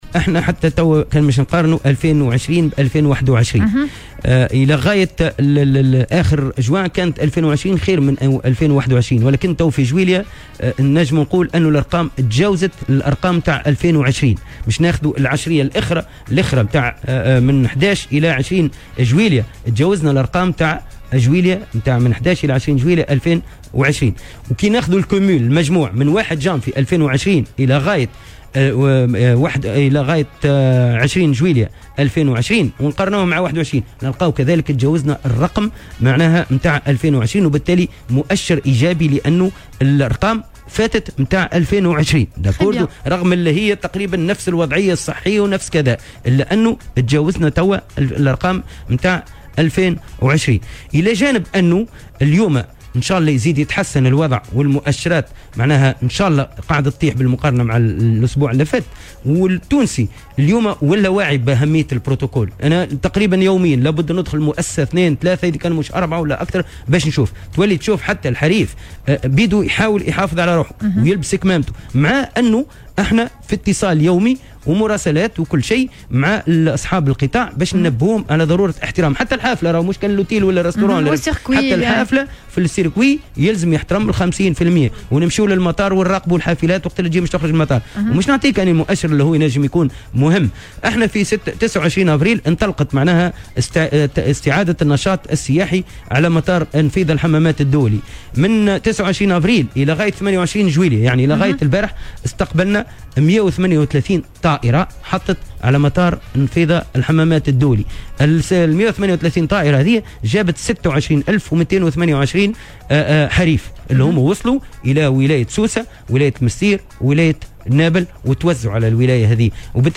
وأضاف في مداخلة له اليوم على "الجوهرة أف ام" أن مطار النفيضة الحمامات الدولي قد استقبل خلال الفترة الممتدة من 29 أفريل إلى يوم أمس 28 جويلية، 138 طائرة، و26228 سائحا تم توزيعهم على ولايات سوسة والمنستير ونابل (من روسيا وألمانيا وبلغاريا وفرنسا..).وشدّد أيضا على أهمية تطبيق البروتوكول الصحي داخل الوحدات الفندقية والحرص على التقيّد بالإجراءات الوقائية.